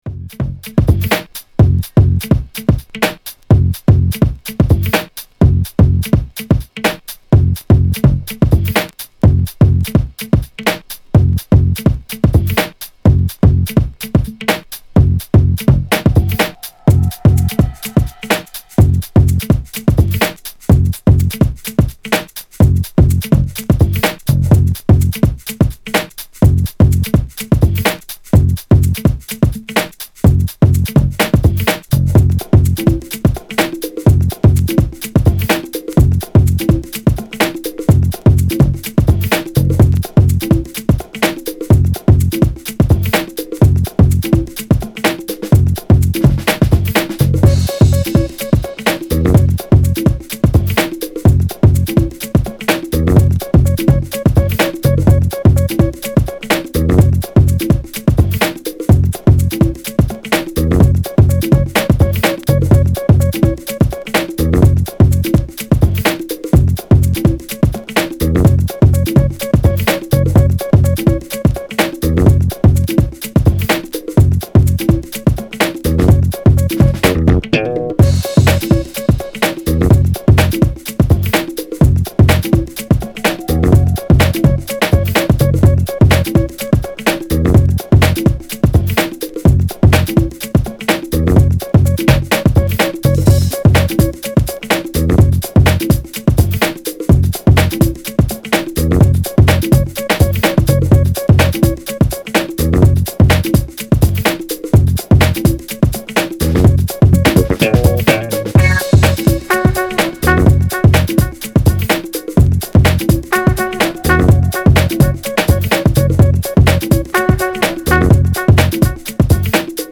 House / Techno